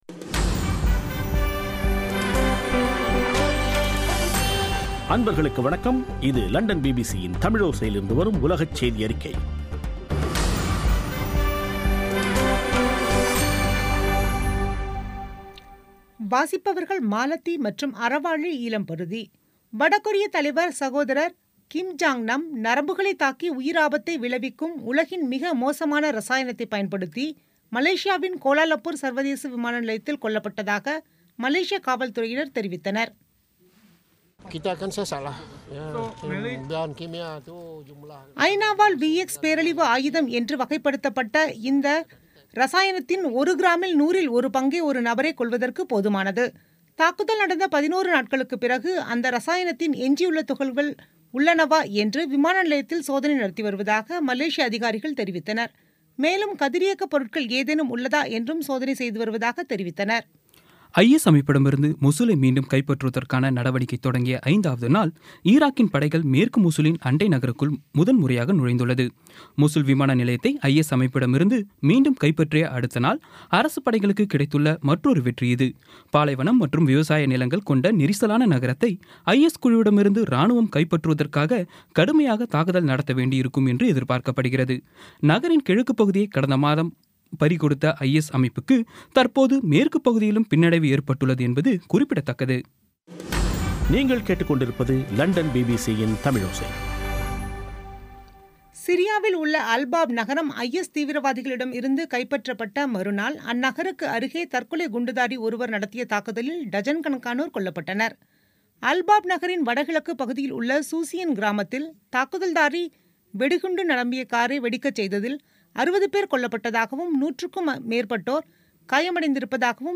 பிபிசி தமிழோசை செய்தியறிக்கை (24/02/17)